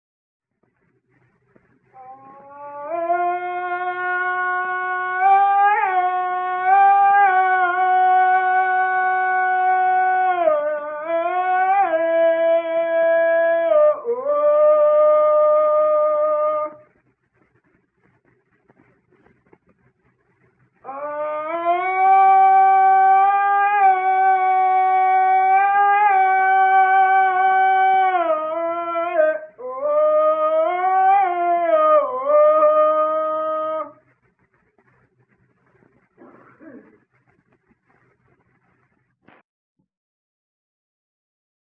Aboio